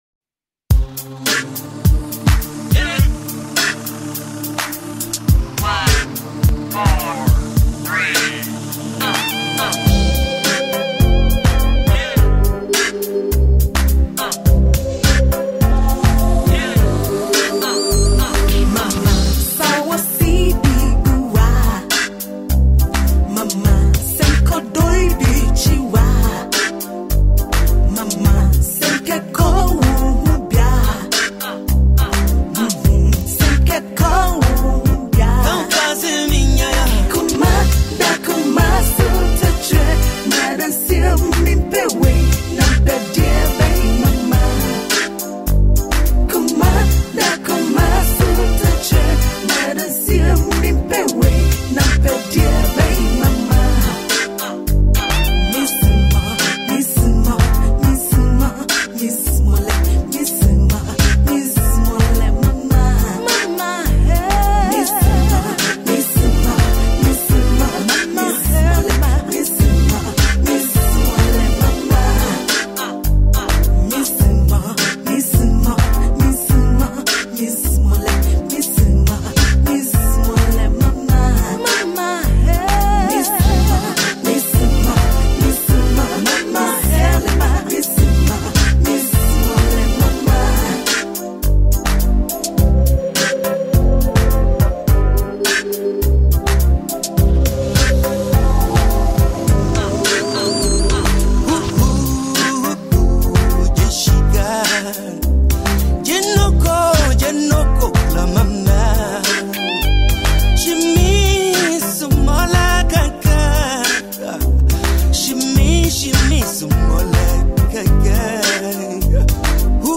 • Perfect for lovers of mature, soulful African music.